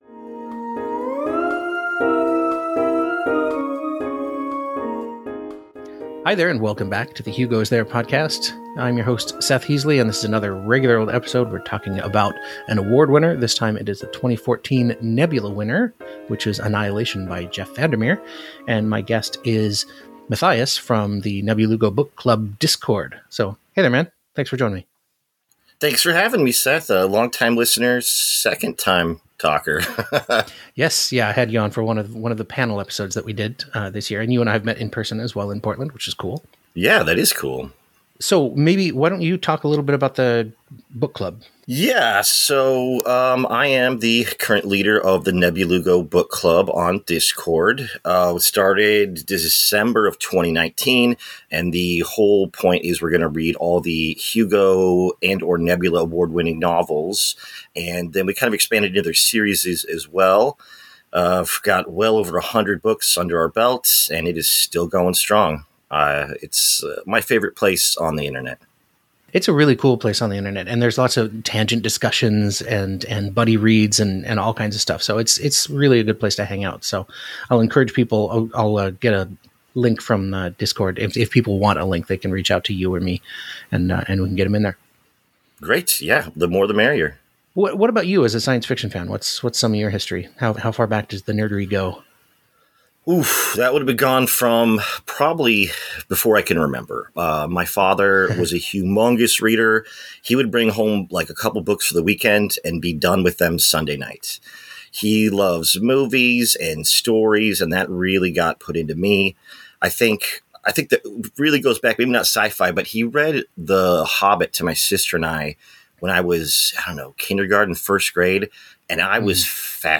joins me for a discussion of Jeff Vandermeer’s 2014 Nebula-winner, Annihilation.